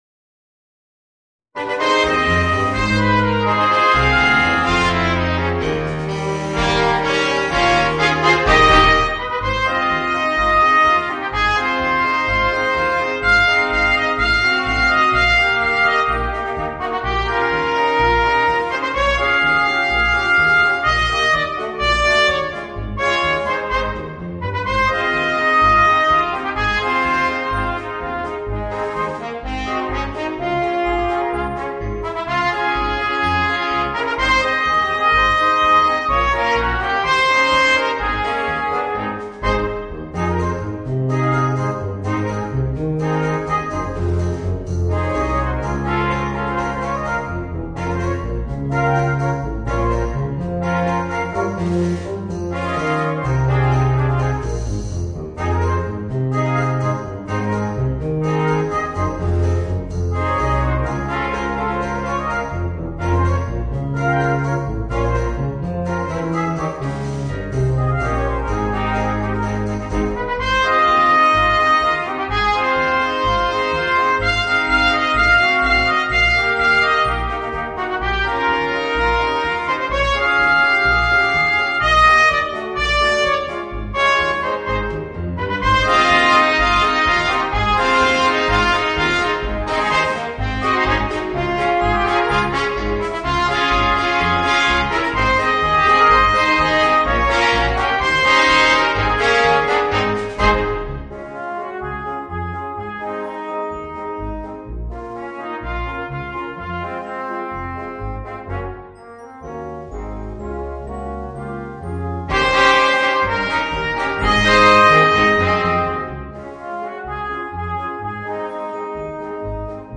Voicing: 2 Trumpets, Horn and Trombone